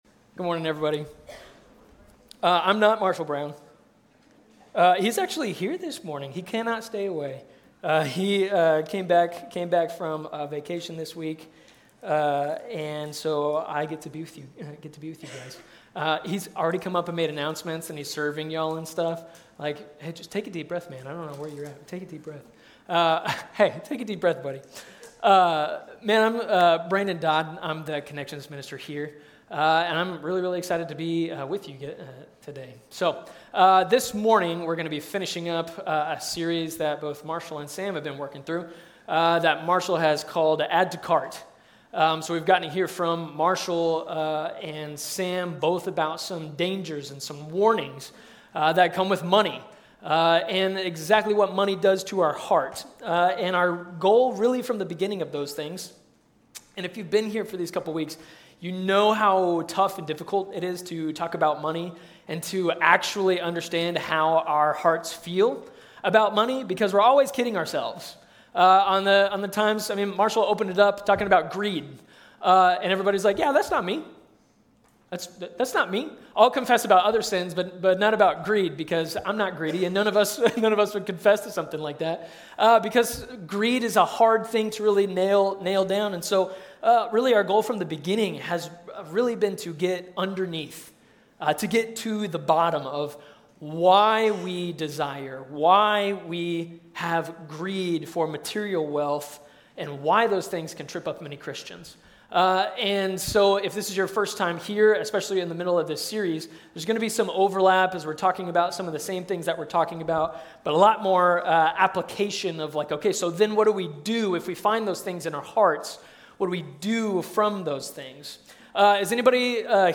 A message from the series "Slow Fade."